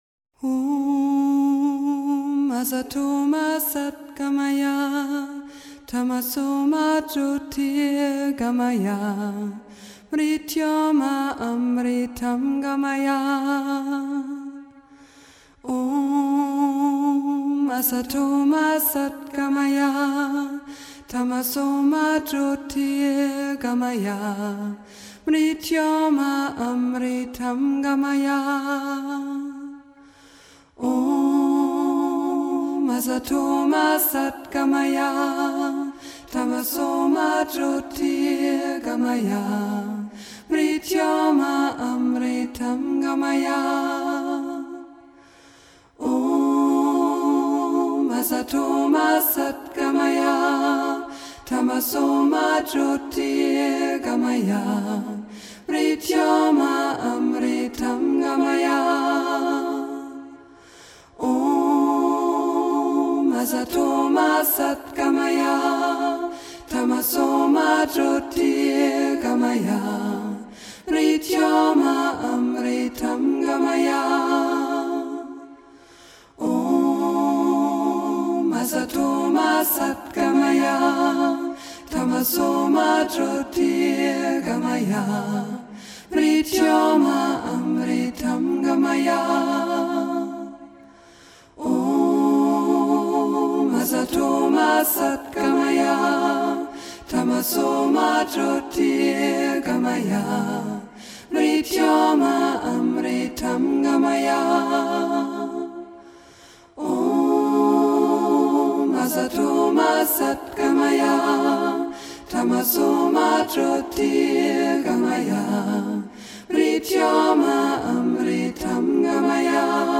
New age Медитативная музыка